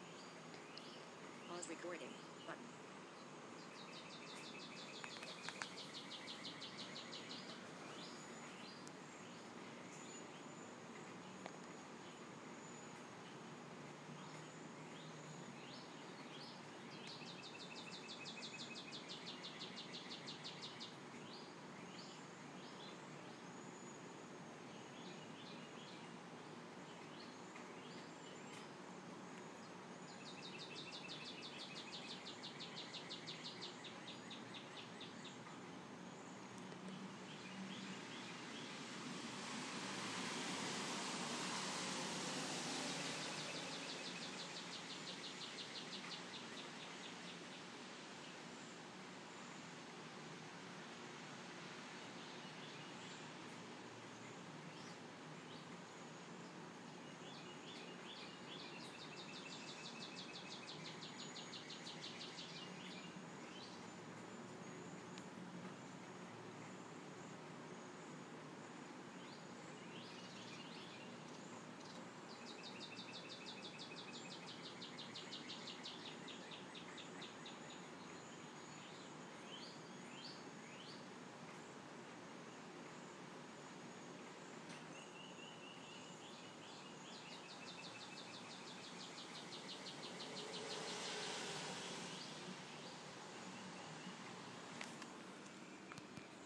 Cardinals Cedarwaxwing and other birds on March 23, 2012